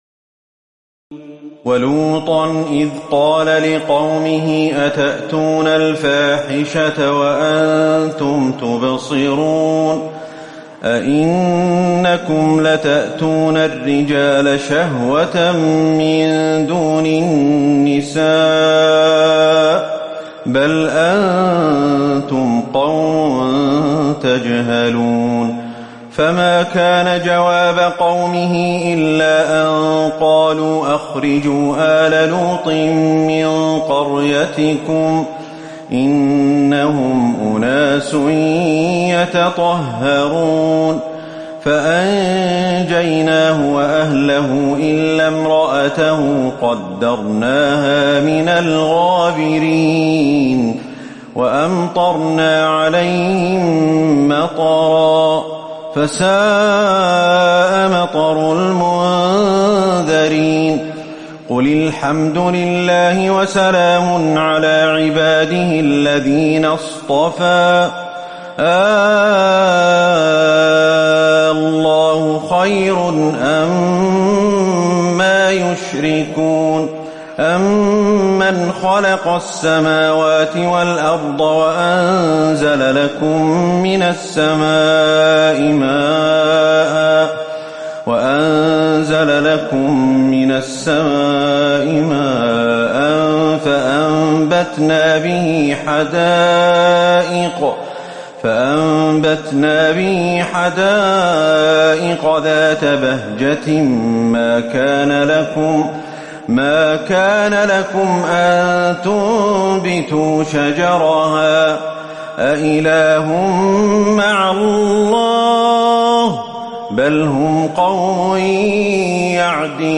تراويح الليلة التاسعة عشر رمضان 1438هـ من سورتي النمل(54-93) و القصص(1-50) Taraweeh 19 st night Ramadan 1438H from Surah An-Naml and Al-Qasas > تراويح الحرم النبوي عام 1438 🕌 > التراويح - تلاوات الحرمين